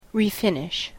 /riˈfɪnɪʃ(米国英語), ri:ˈfɪnɪʃ(英国英語)/